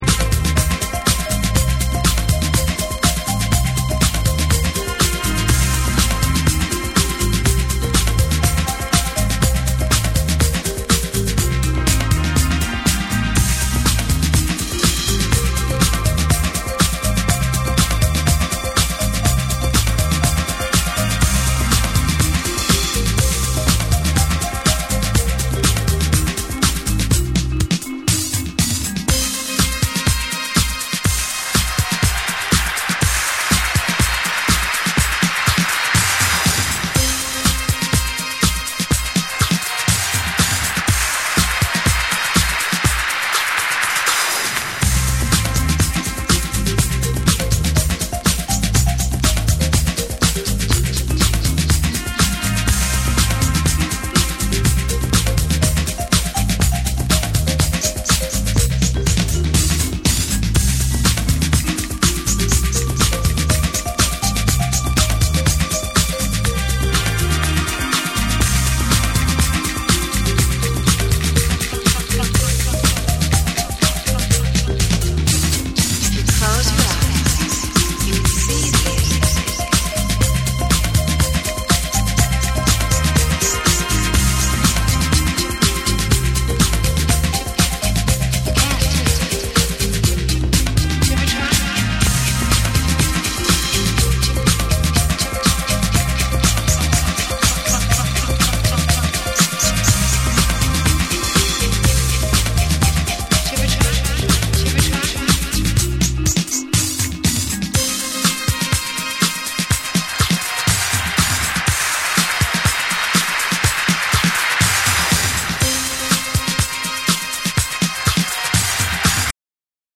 ミニマルなパーカッションにエフェクト処理がじわじわ効いてくるサイケ〜ダビーな展開、ブロークン気味のグルーヴが最高です！
TECHNO & HOUSE / DISCO DUB